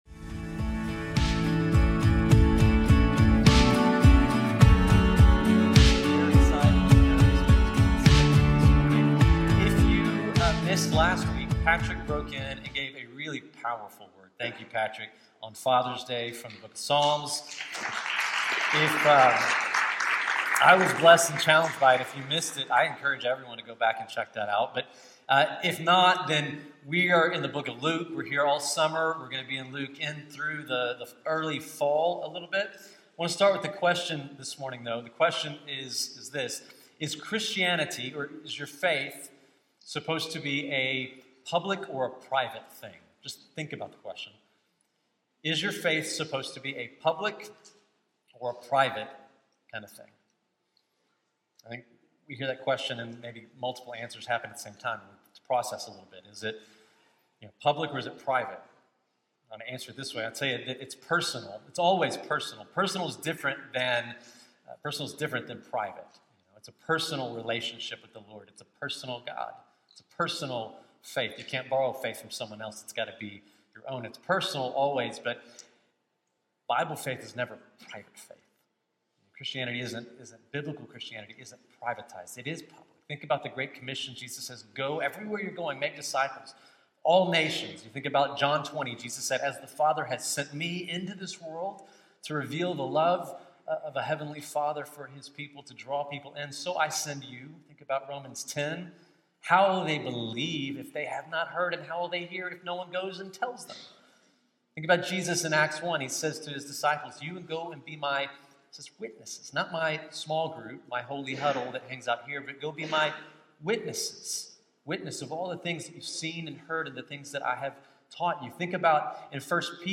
This powerful sermon from Luke 3 answers with conviction: Faith may be personal, but it’s never meant to be private. Through the bold ministry of John the Baptist and Jesus’ own public baptism, we are confronted with a call to step out of moderation and into mission.